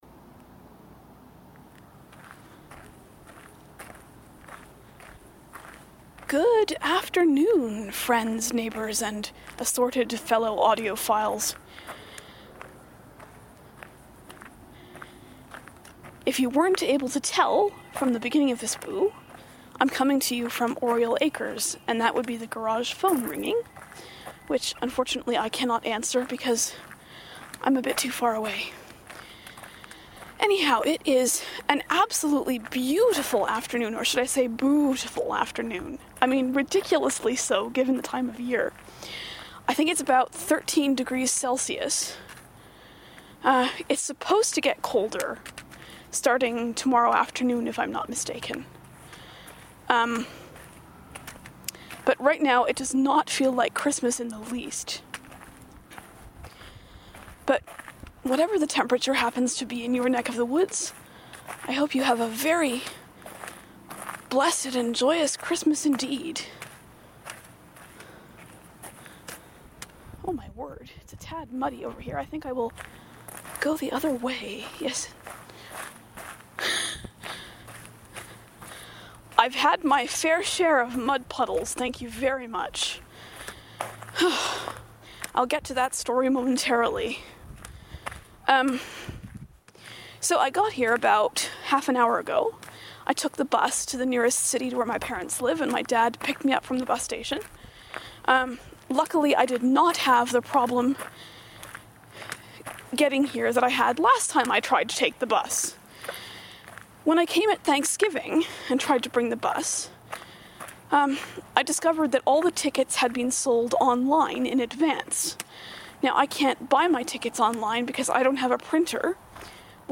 Back on the Farm; hope you'll pardon the wind noise
I hope you're all able to hear me during the windier parts of this post. I really need to get a windsock for this Olympus.